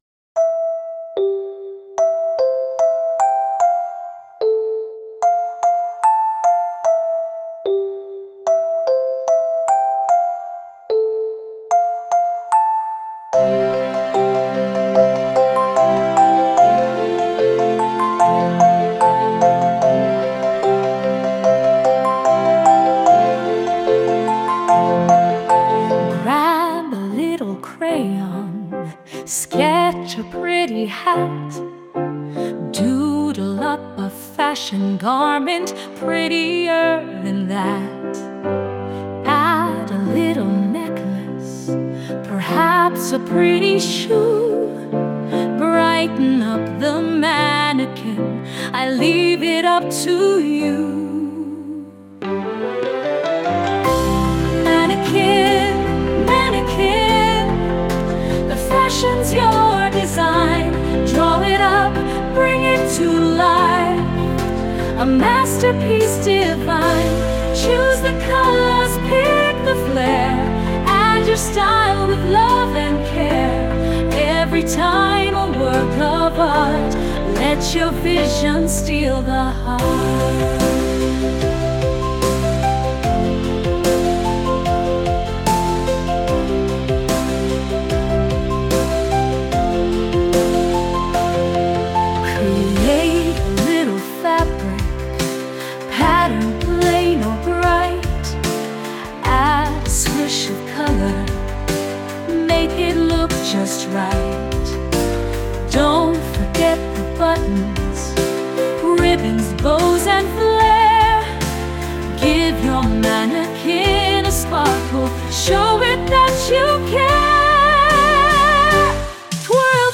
Lyrics started by me, expanded by ChatGPT
Live version